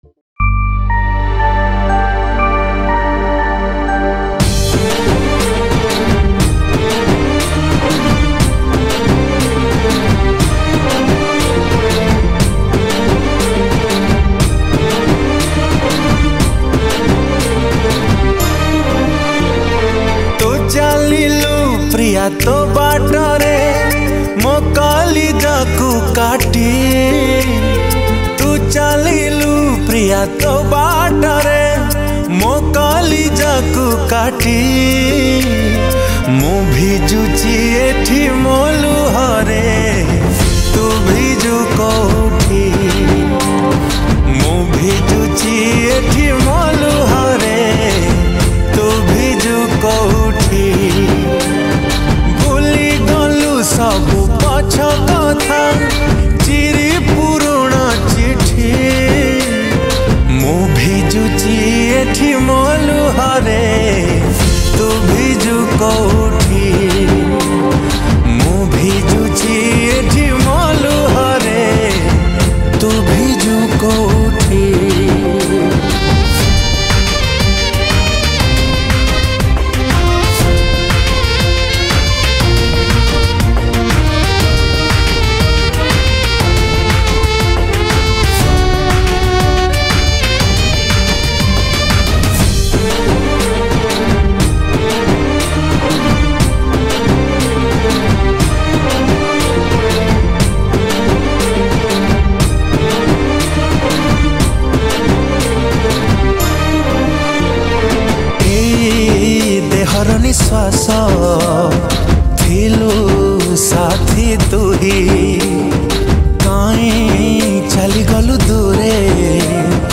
Odia Sad Romantic Songs